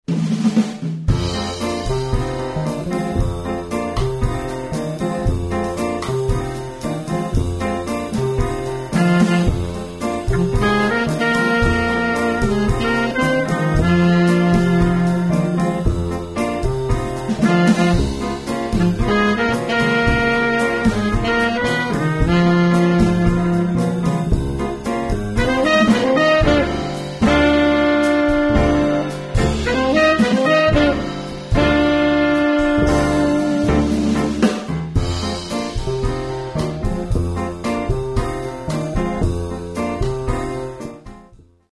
Hot and Cool Jazz, Swing and Bebop Music
Piano
Trumpet
Tenor Sax
Bass
Drums